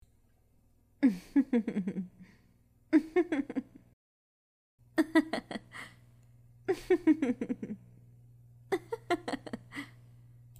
• Female Laugh
Joyful laughter to lighten the mood and start smiling
laughter, woman
FemaleLaugh.mp3